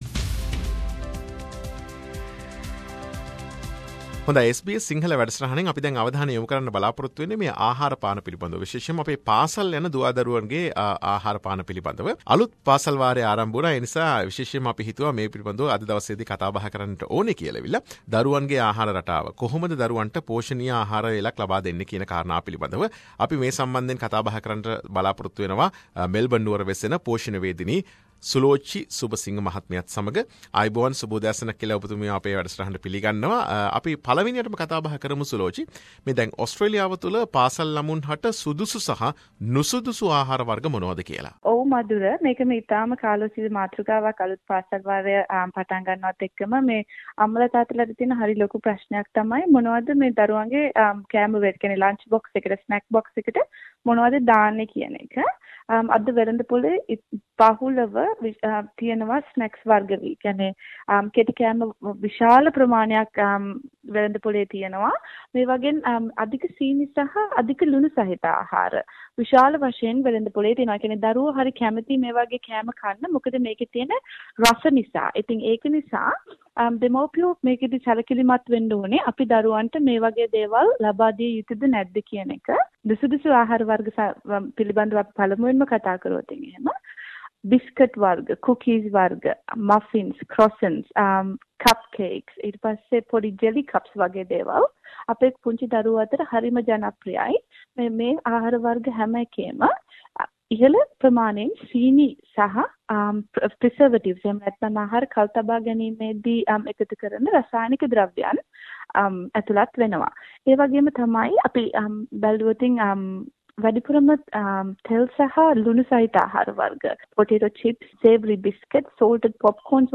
SBS සිංහල ගෙන එන සාකච්ඡාව.